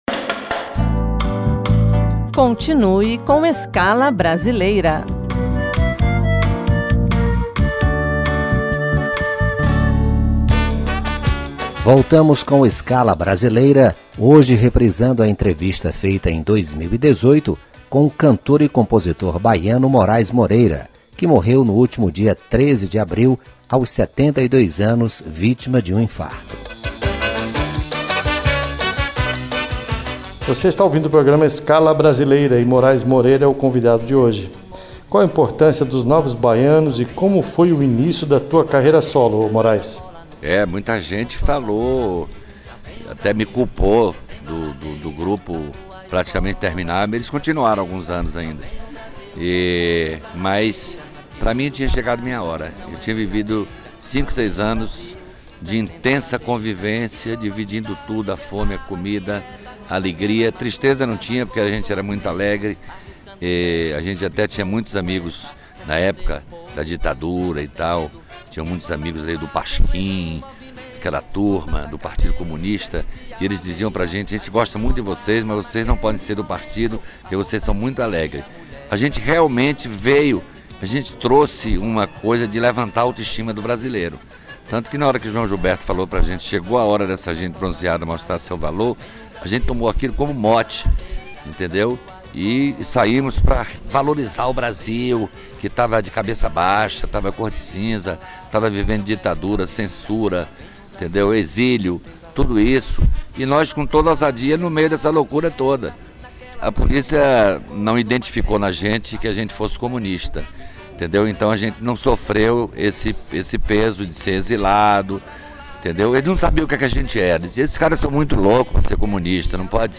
O programa Escala Brasileira recebeu Moraes Moreira em março de 2018 para um conversa sobre a sua trajetória.